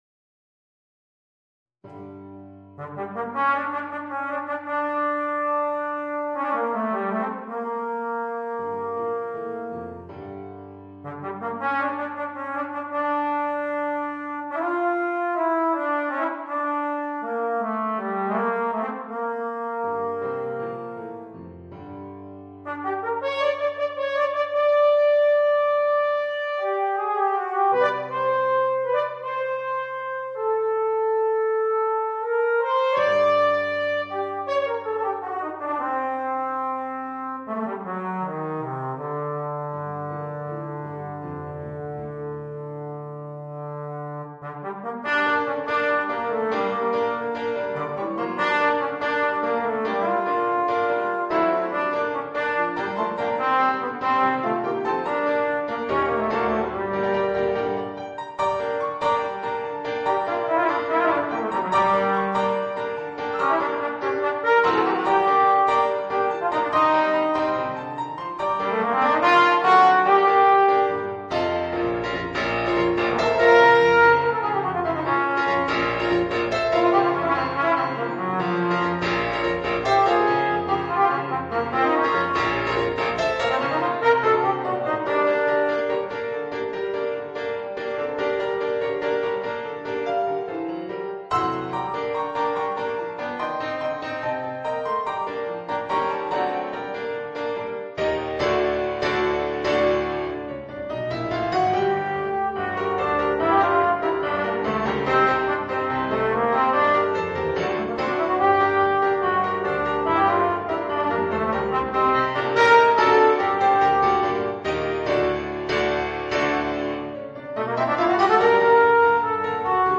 Voicing: Trombone and Piano or CD